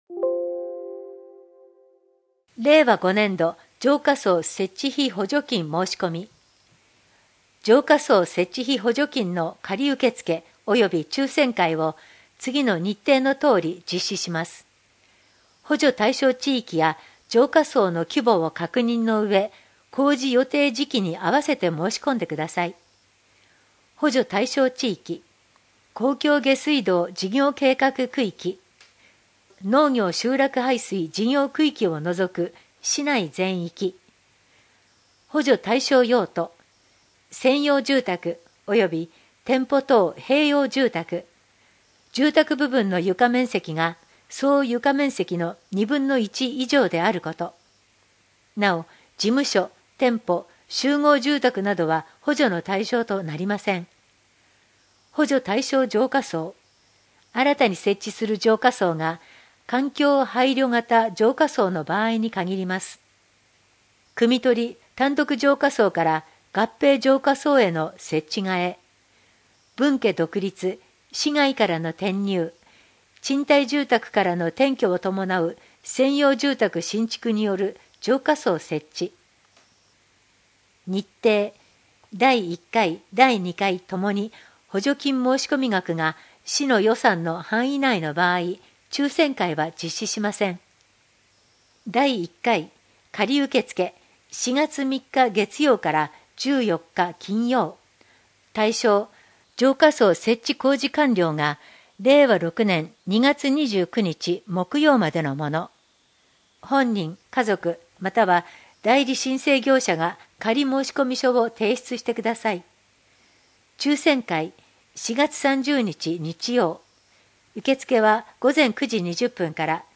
声の広報は、朗読ボランティア「野ばらの会」様のご協力により、目の不自由な人や高齢者など、広報紙を読むことが困難な人のために「声の広報筑西People」としてお届けしています。